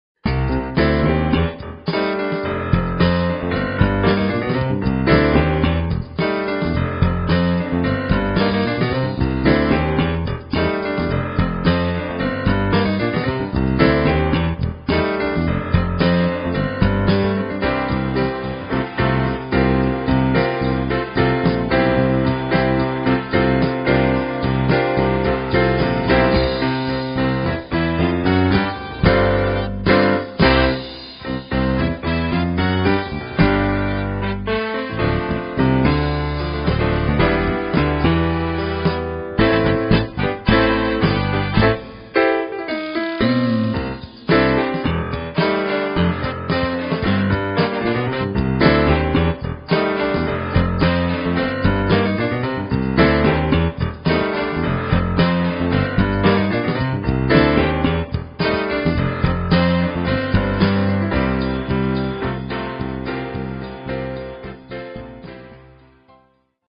Jazz Rock